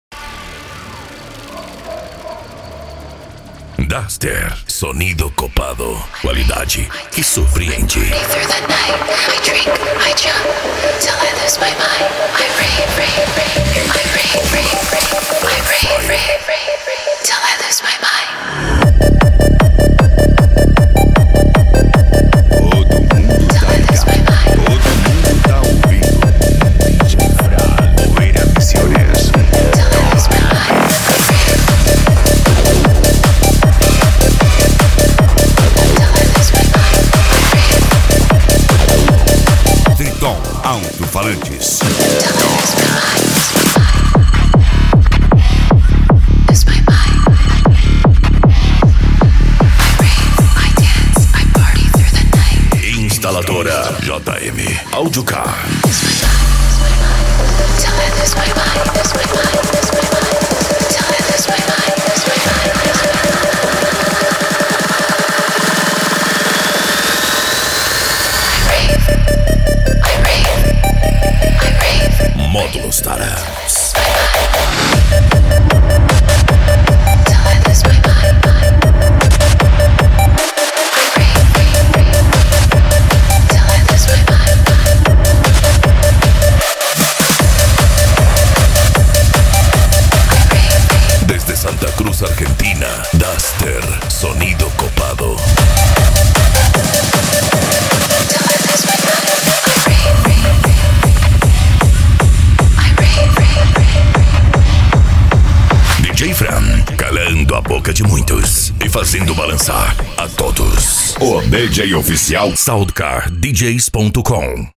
Eletronica
Psy Trance
Remix